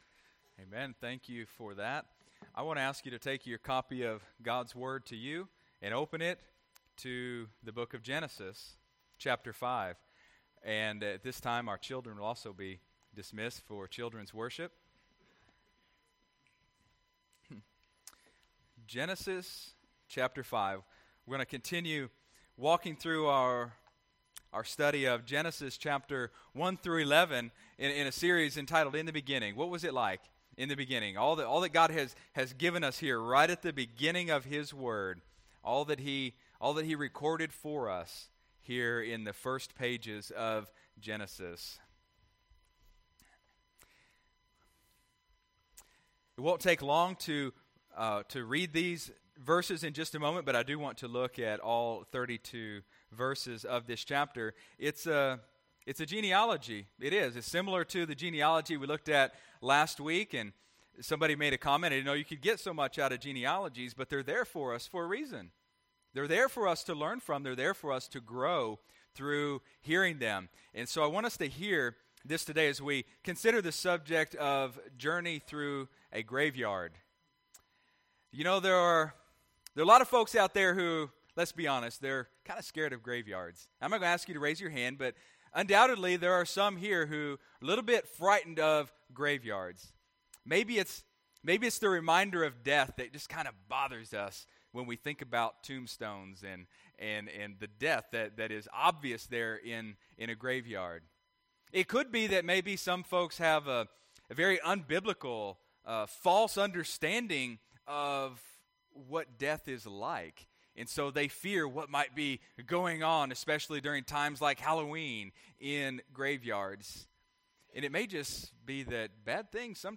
Sunday, February 7, 2016 (Sunday Morning Service)